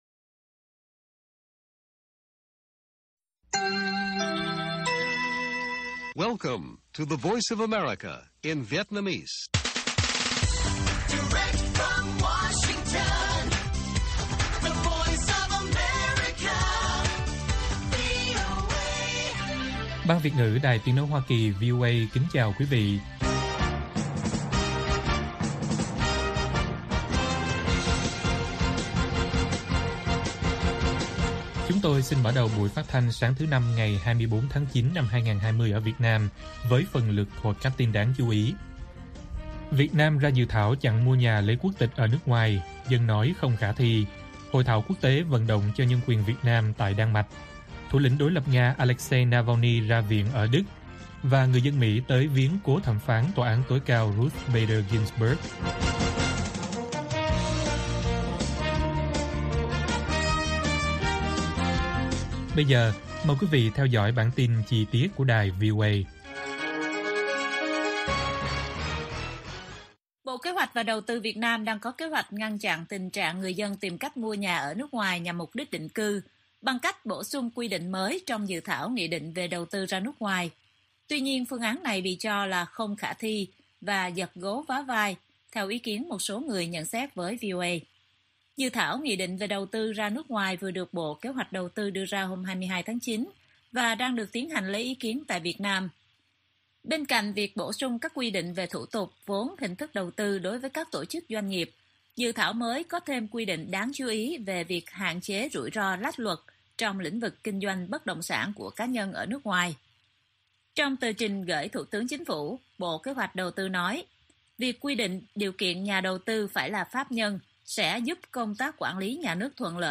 Bản tin VOA ngày 24/9/2020